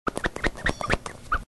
Звук выходящего воздуха из надувной игрушки